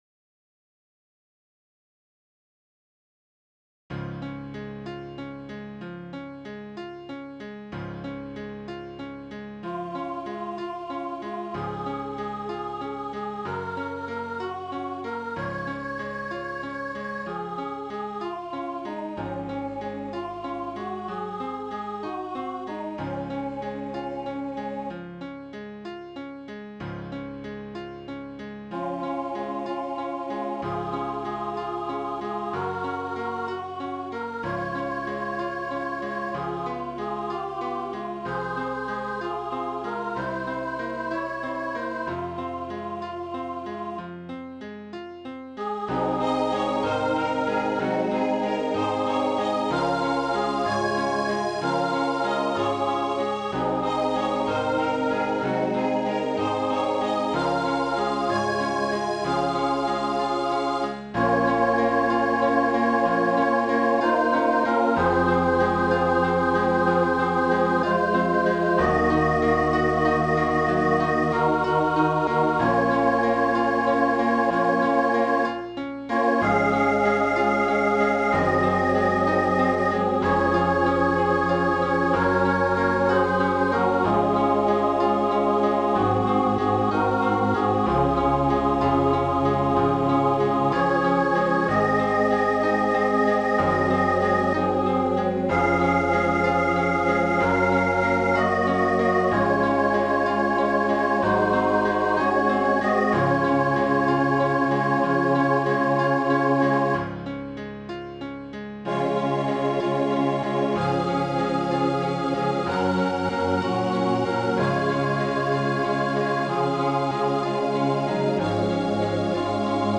Features 2 violins and 1 cello.